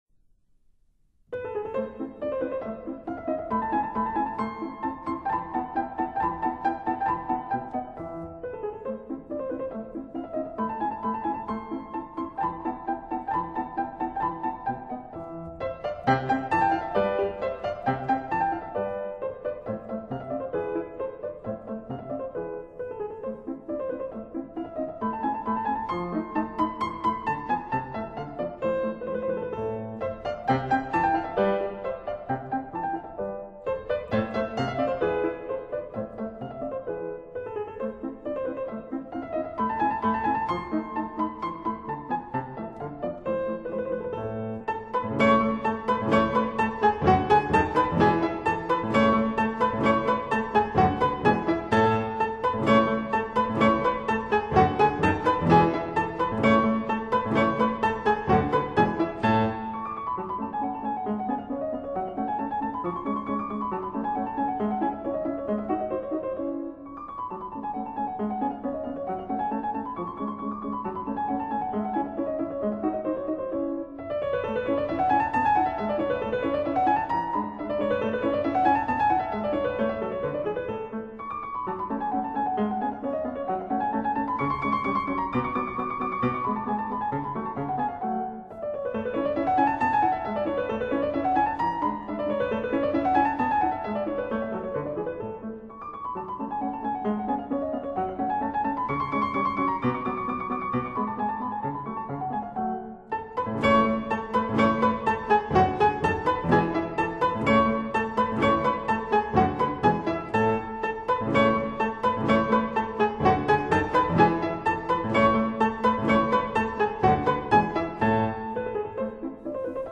这种坦率的固执己见，或者说“保守主义”的态度，使得她的演奏听起来严谨慎重，但又不乏明晰优雅的轻松气质。